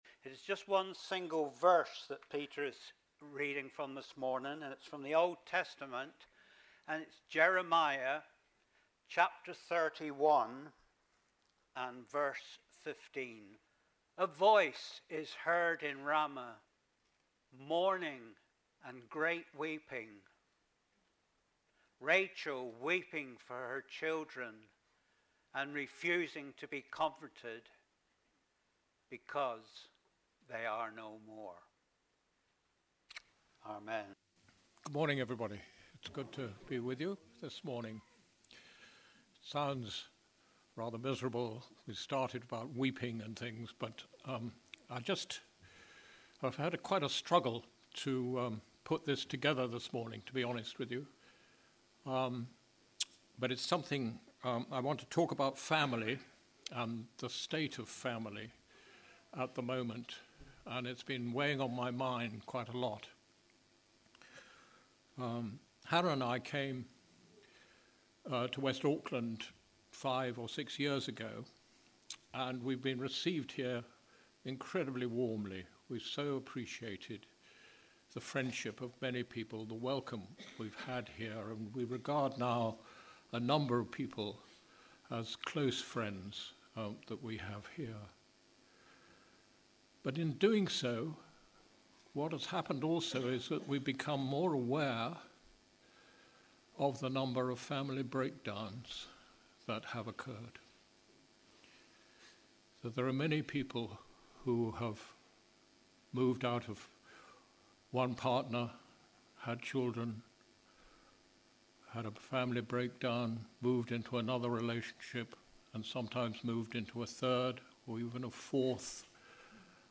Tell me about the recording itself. Here is the link to the bible reading being read at the beginning Jeremiah 31:15